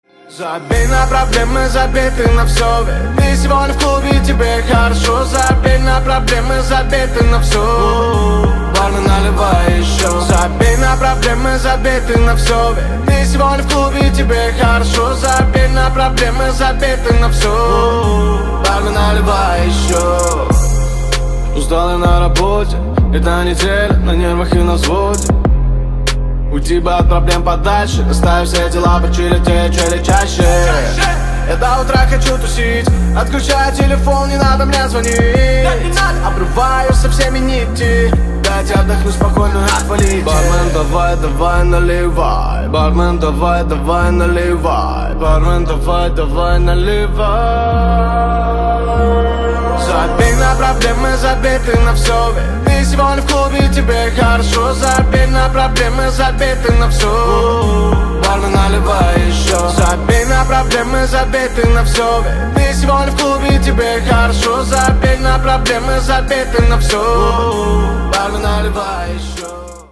• Качество: 224, Stereo
мужской вокал
громкие
Хип-хоп
русский рэп
басы